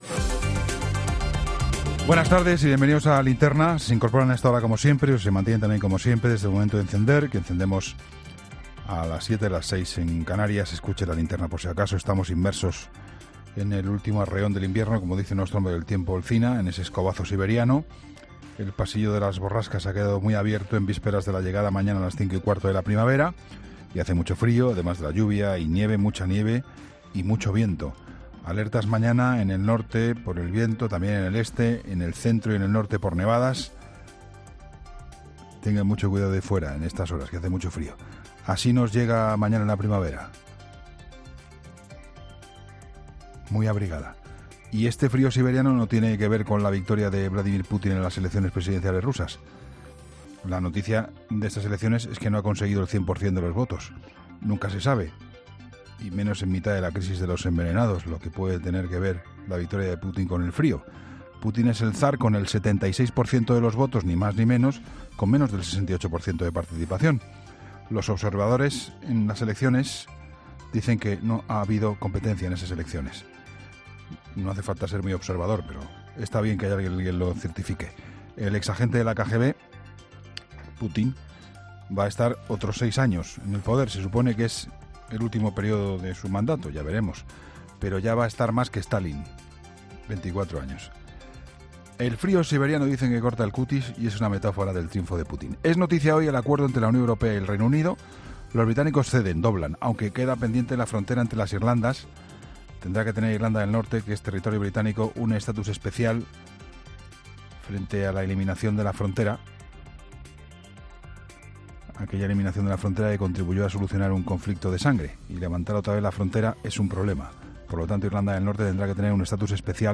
La crónica